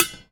R - Foley 42.wav